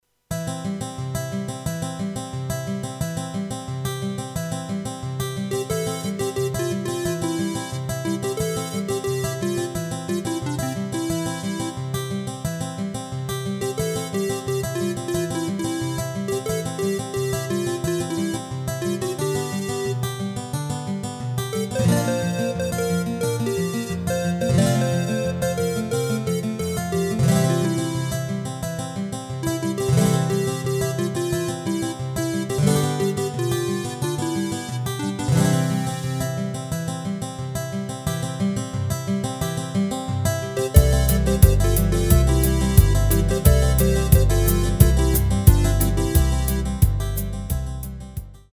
Rubrika: Folk, Country
Karaoke
HUDEBNÍ PODKLADY V AUDIO A VIDEO SOUBORECH